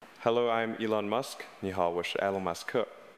给智能硬件们装上“AI声带”的Speech-02模型属于TTS（文本转语音）模型。
第三是专业级声音参考（PVC），只需少量样本就能提取说话者的核心特征，生成高度相似的声音，且每个克隆只需存储极少数据。在智东西的实测中，下方的埃隆·马斯克声音就仅仅使用了一段20秒的音频作为参考。